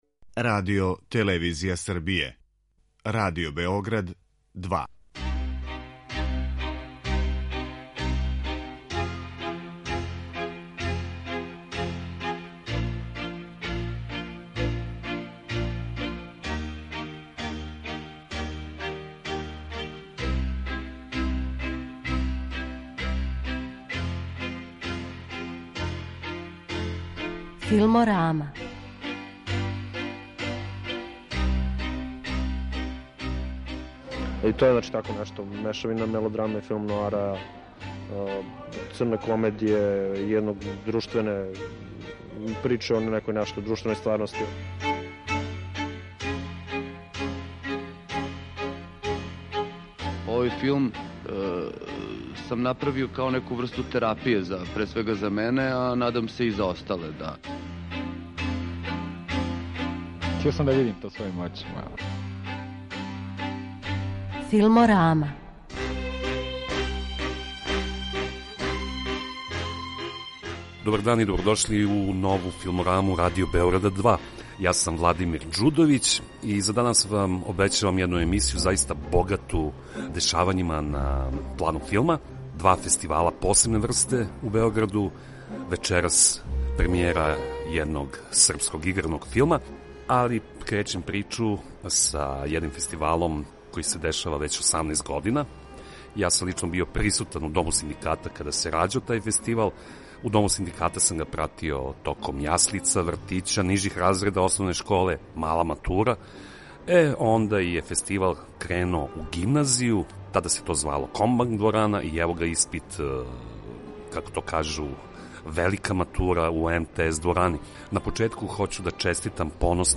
Доносимо репортажу са те значајне, необичне, и у светским оквирима ретке филмске мисије.